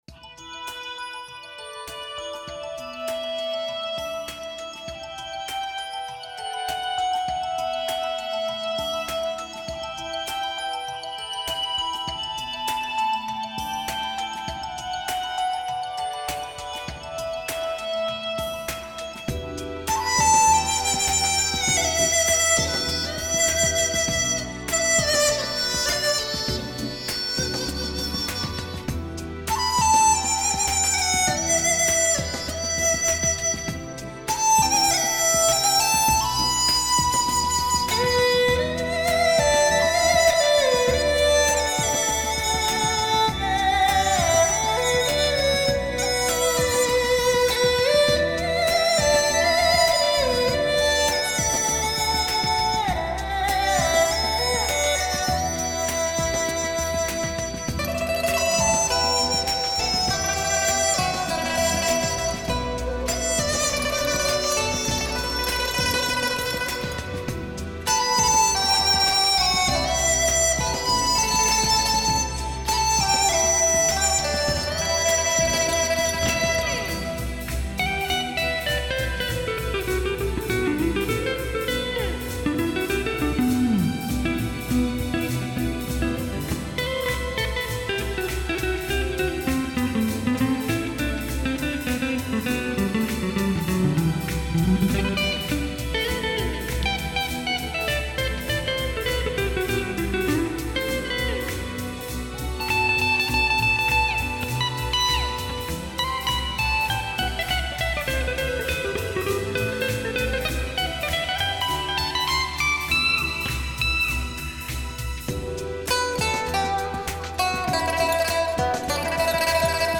采用美国最新核心专利技术独立七声道扩
民乐演奏家的跨界演绎，
古典乐器的流行表情，突破传统与时尚、
中国最年轻技艺最精湛的女子民乐演奏家组合，